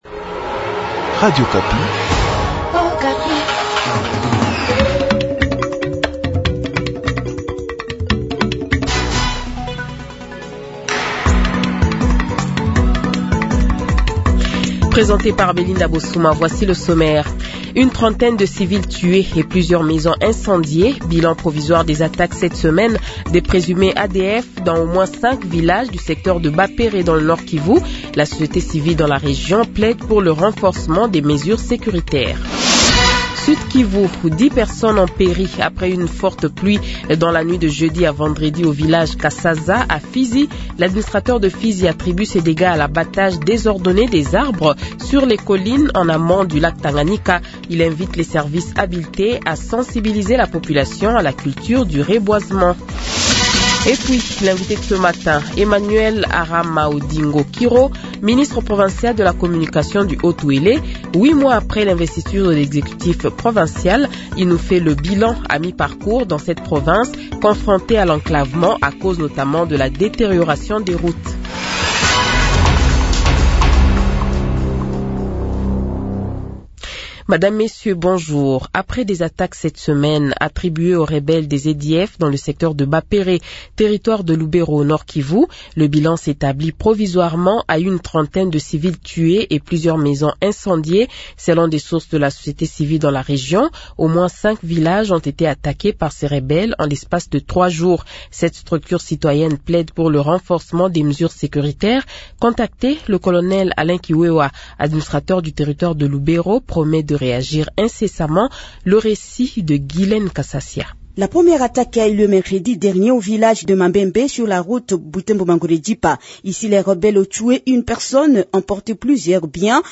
Journal Francais Matin